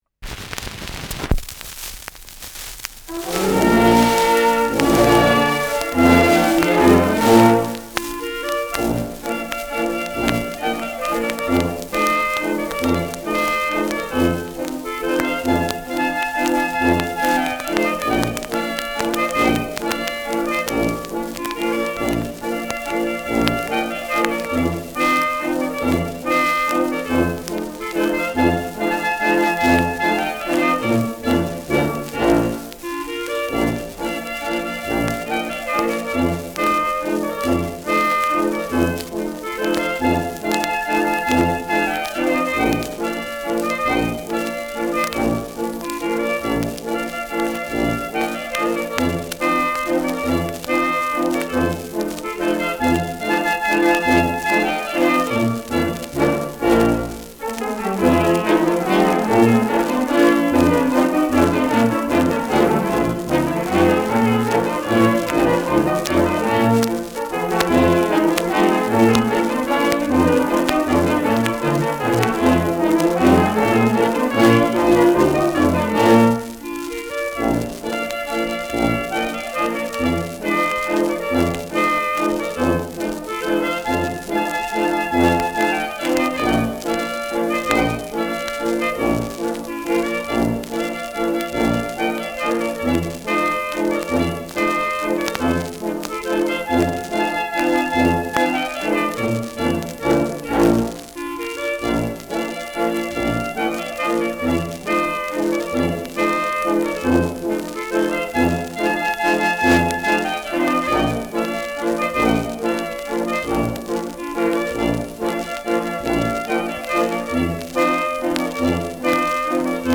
Schellackplatte
Abgespielt : Nadelgeräusch : durchgehend stärkeres Knacken
Kapelle Almenrausch, München (Interpretation)
Möglicherweise für Tanzgruppen produziert, da auffällig langsam.
[München] (Aufnahmeort)